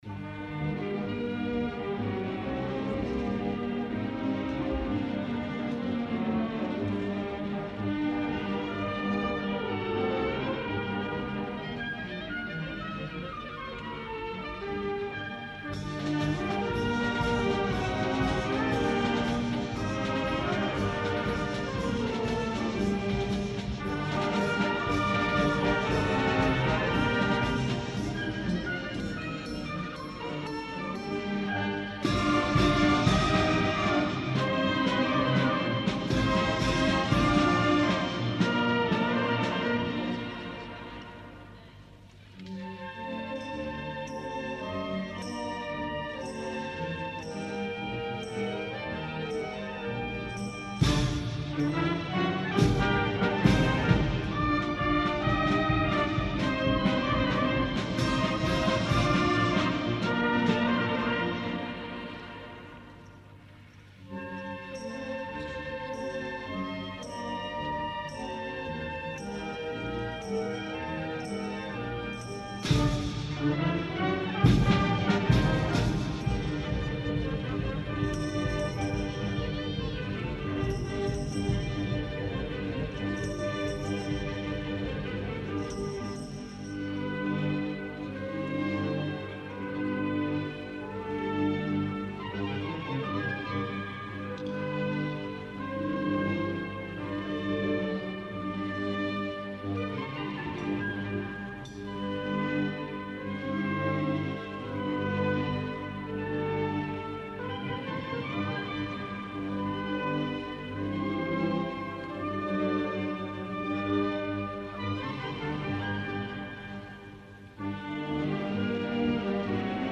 Concert de Sa Fira a l'Esglèsia de la Nostra Senyora de la Consolació